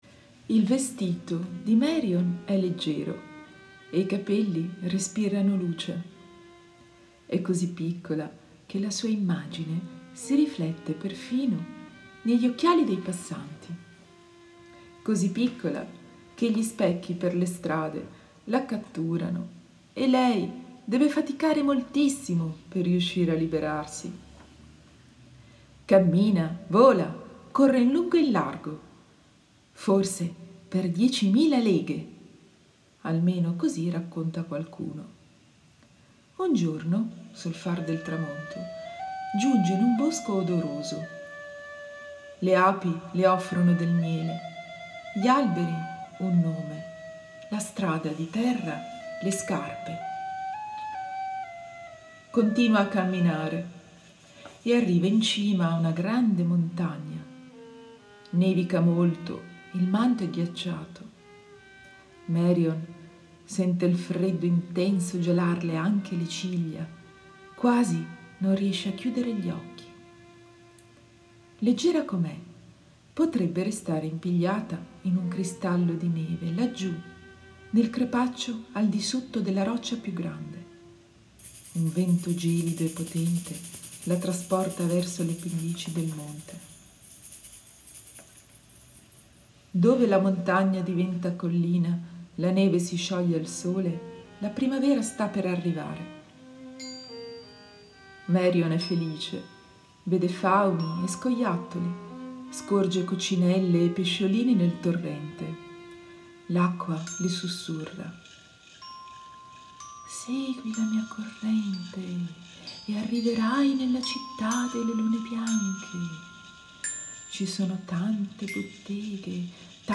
Audiolibri